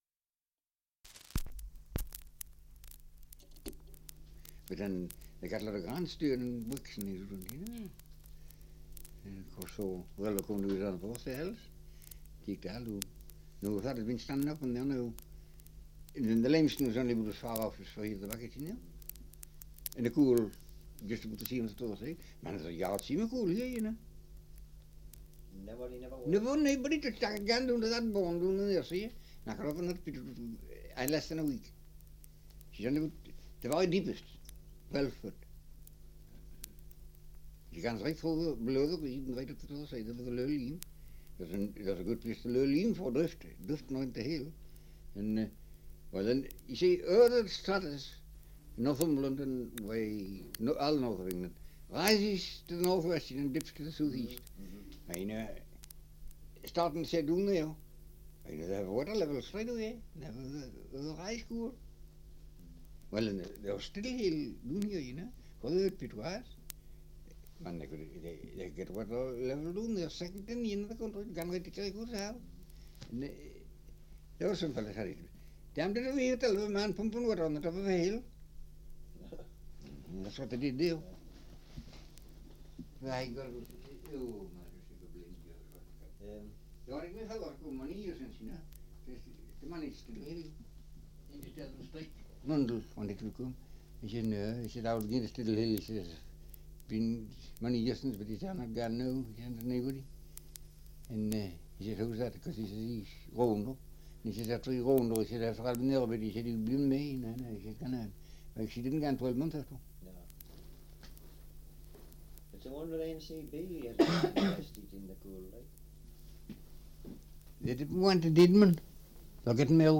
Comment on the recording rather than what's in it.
Survey of English Dialects recording in Wark, Northumberland 78 r.p.m., cellulose nitrate on aluminium